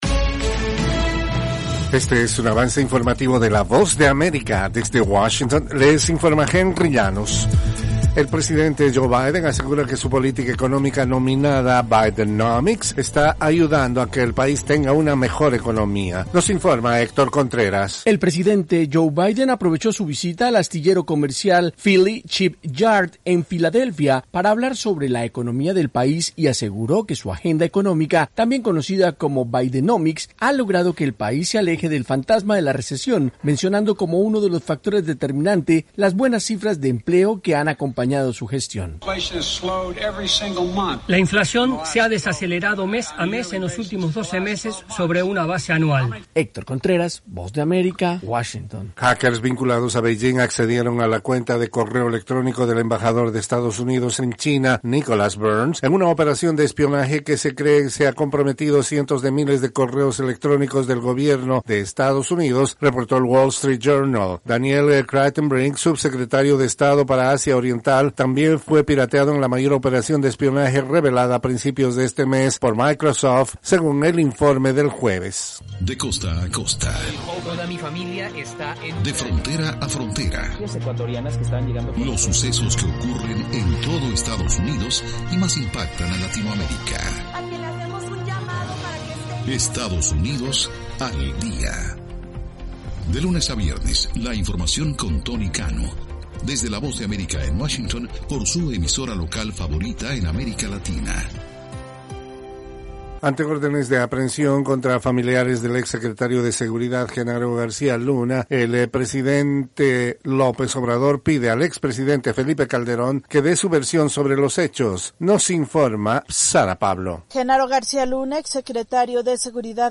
Desde los estudios de la Voz de América en Washington